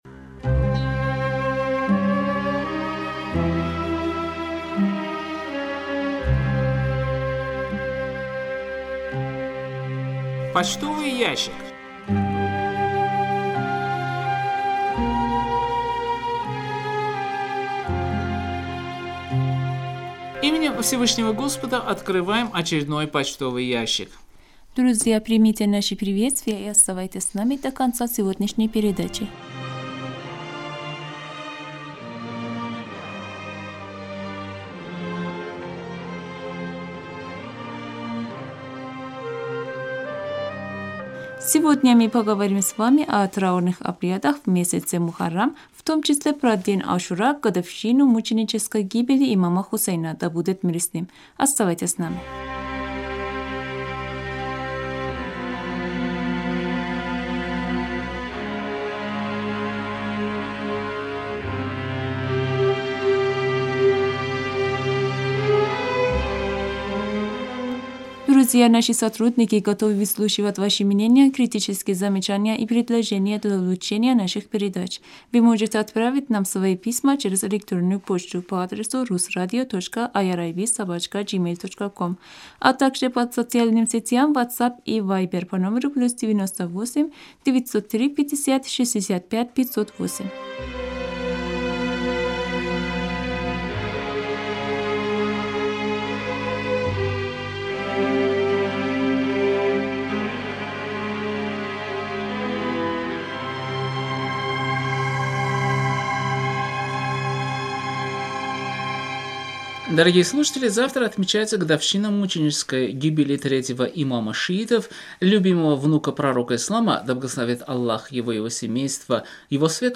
Беседа: